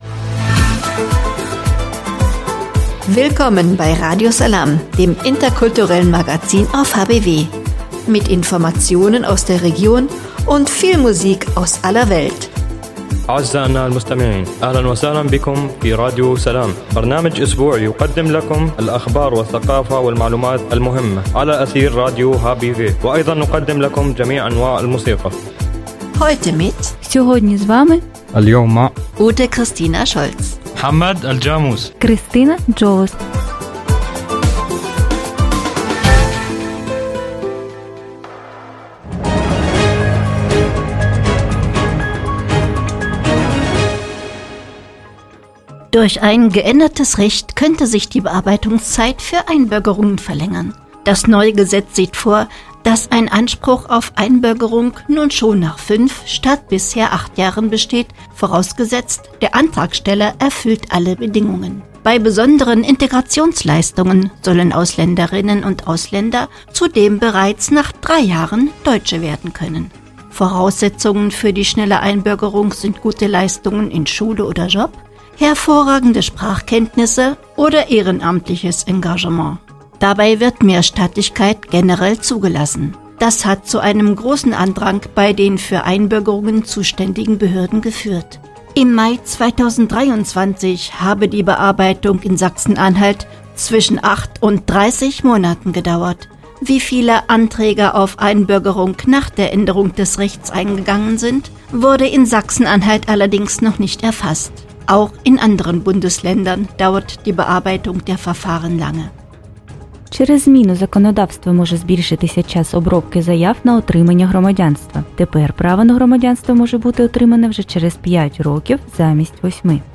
„Radio Salām“ heißt das interkulturelle Magazin auf radio hbw.